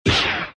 Звуки скретча
Звук: музыку поставили на паузу нажатием кнопки pause